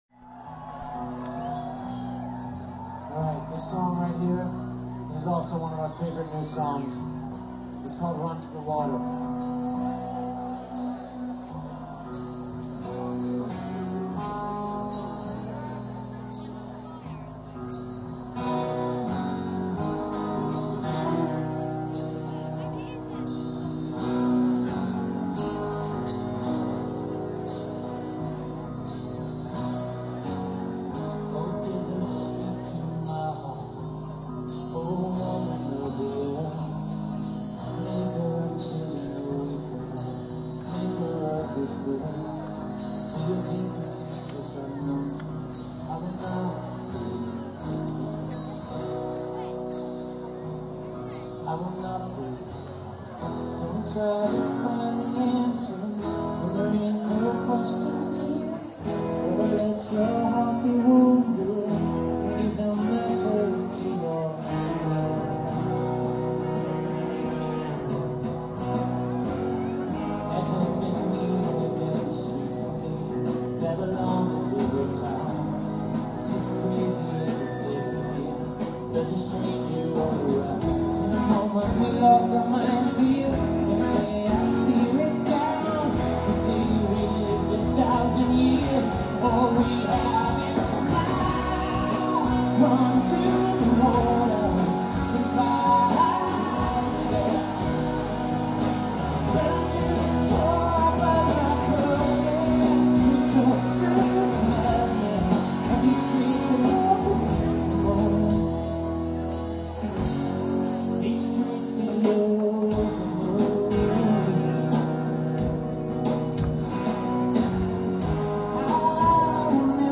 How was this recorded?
From The Electric Ballroom London, England (7-1-99)